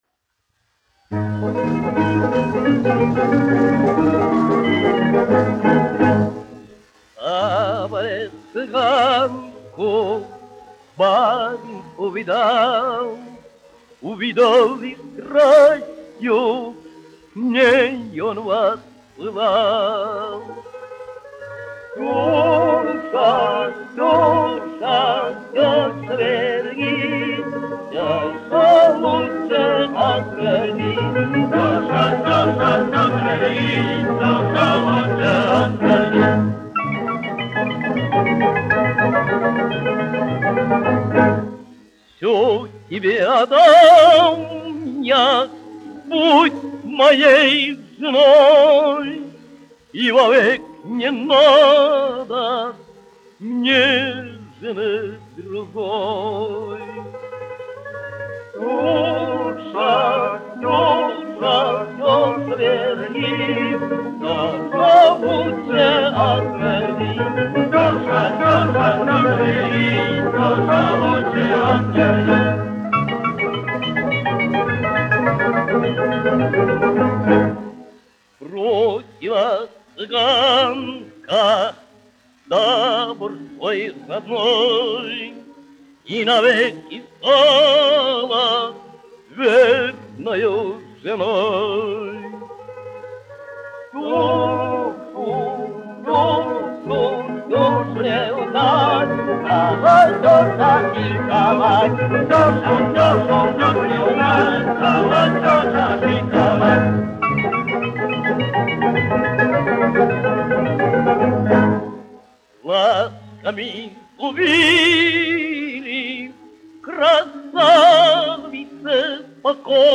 1 skpl. : analogs, 78 apgr/min, mono ; 25 cm
Romances (mūzika)
Čigānu mūzika
Latvijas vēsturiskie šellaka skaņuplašu ieraksti (Kolekcija)